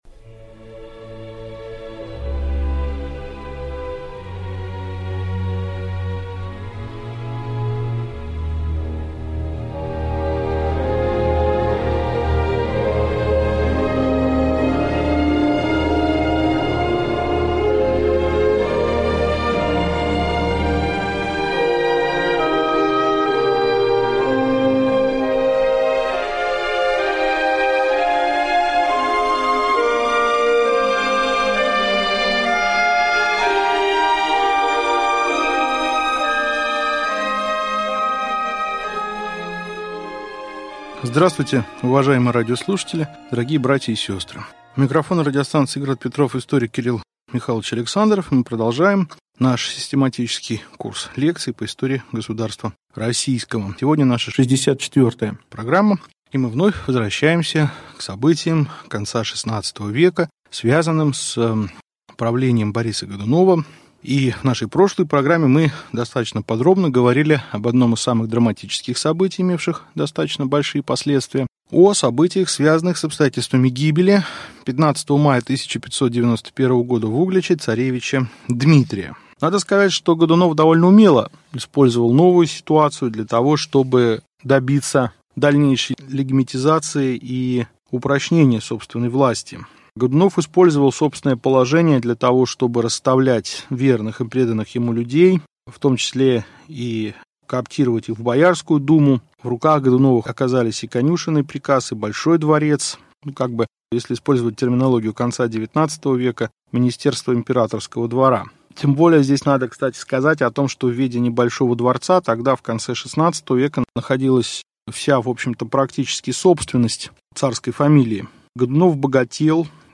Аудиокнига Лекция 64. Крепостное право | Библиотека аудиокниг